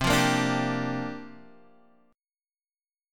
Bb6/C chord